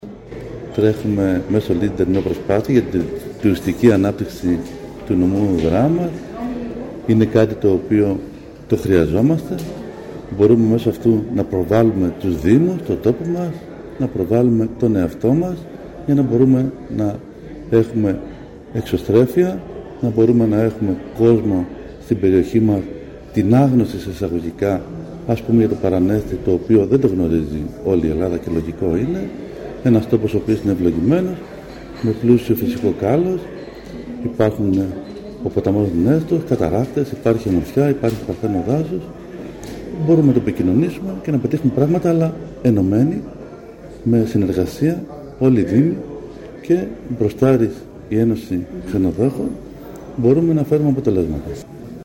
Καγιάογλου Αναστάσιος – Δήμαρχος Παρανεστίου